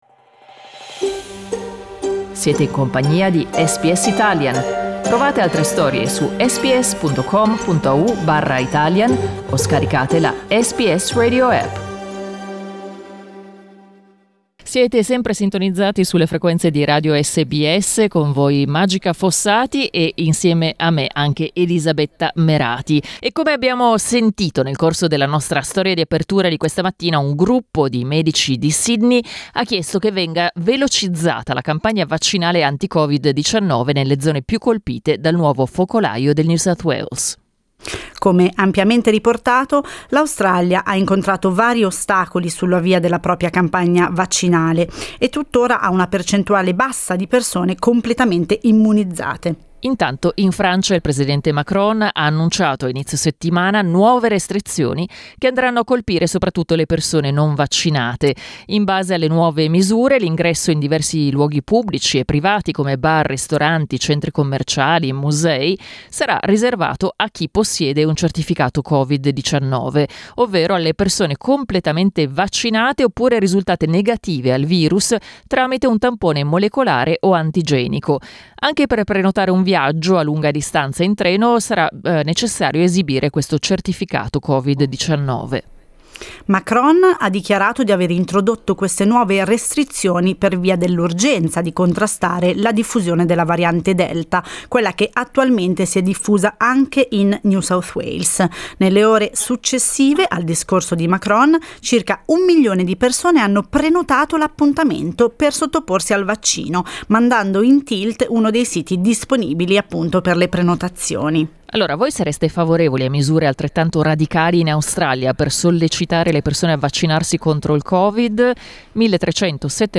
Abbiamo chiesto ad ascoltatori e ascoltatrici se sarebbero favorevoli a misure altrettanto radicali in Australia, per sollecitare le persone a vaccinarsi contro il Covid-19. Ascolta il dibattito: LISTEN TO Vaccini, sareste favorevoli al "green pass"?